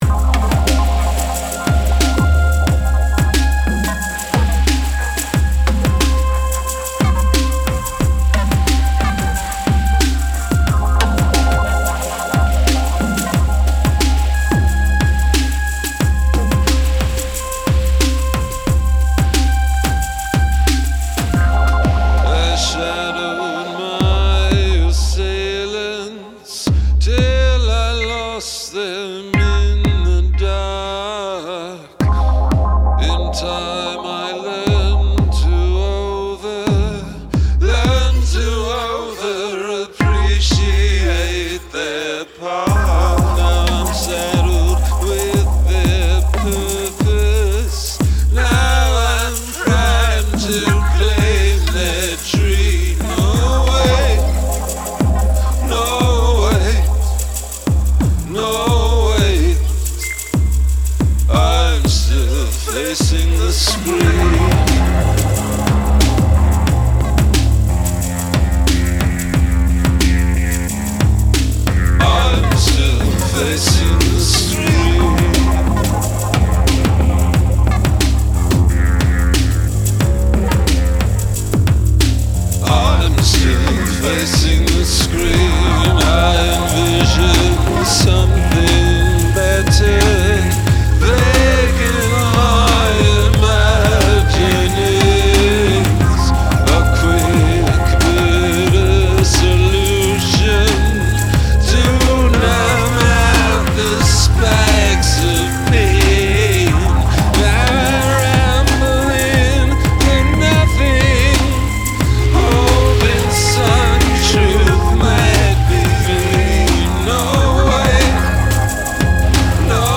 This one is 100% Syntakt except the vocals
This sounds like Bowie bought a Syntakt.